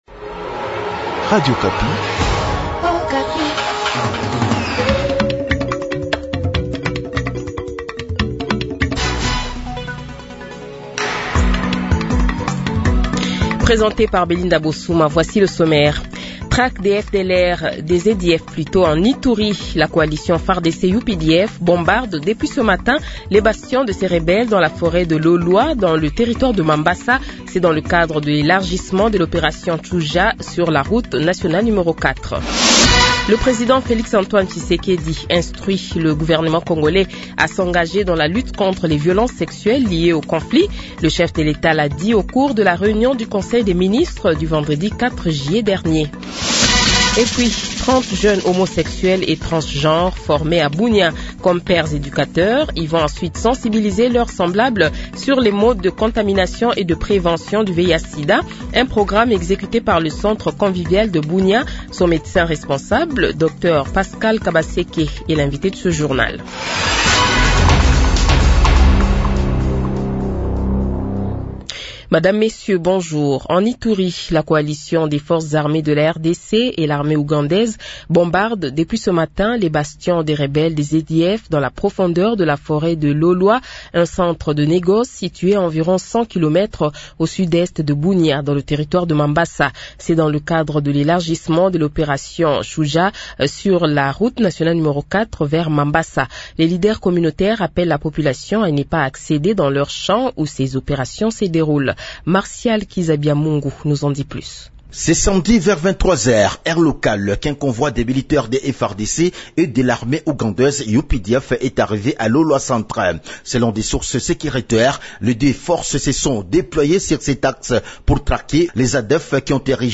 Journal midi du dimanche 6 juillet 2025